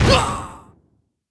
airship_die1.wav